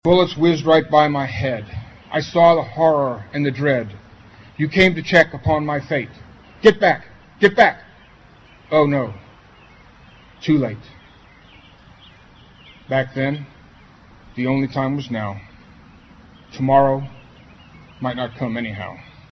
Under a partly cloudy sky, Emporians gathered at the All Veterans Memorial to pay their respects to military service personnel who never made it home from combat as well as those who passed away over the last year.
Another reading